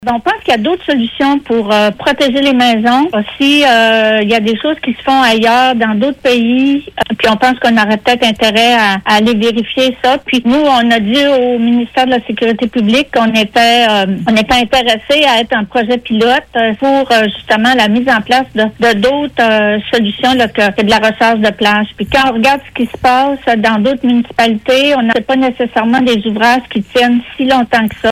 Écoutons ici la mairesse de Sainte-Luce, Micheline Barriault :